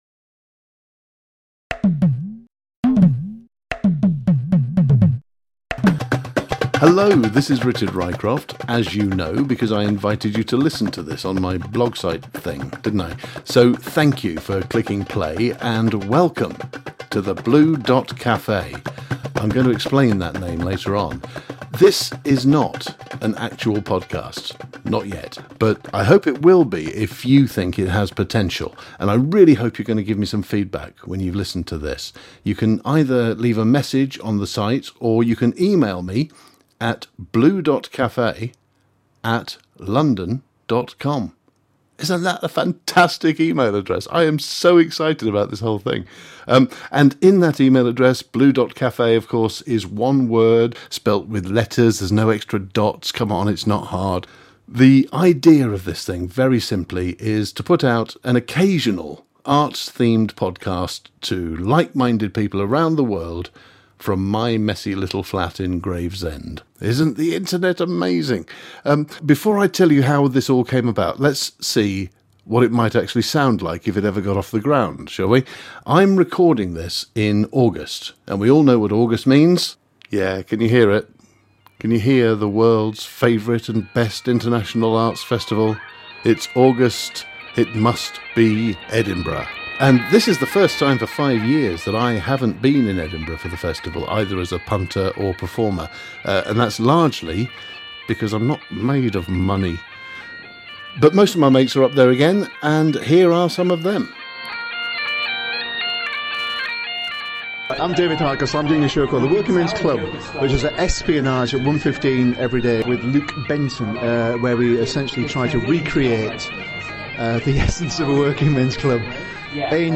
Is my technical quality acceptable? I had a few issues with it, some of which I can overcome next time, but I’m pretty sure I’ve heard worse than this.